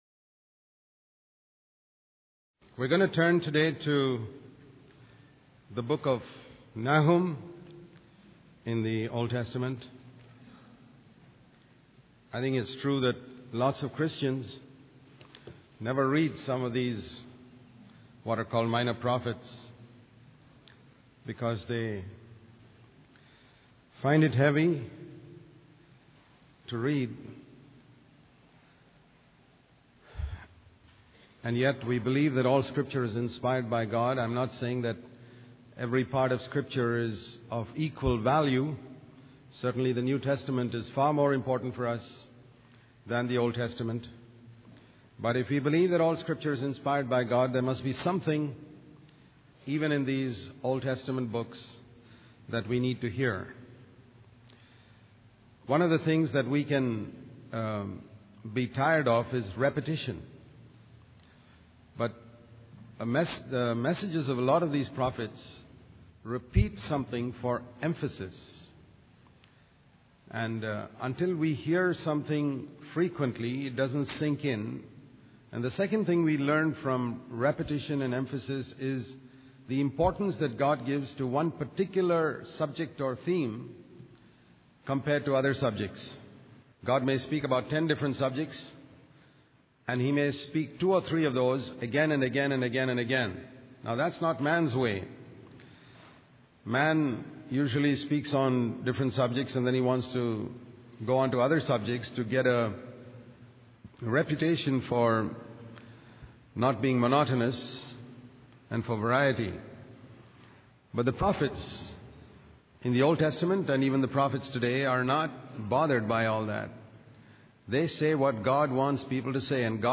In this sermon, the speaker addresses the frustration and confusion that believers often feel when they see the wicked prospering and the righteous suffering. The speaker uses the example of the prophet Habakkuk, who questioned God about this injustice.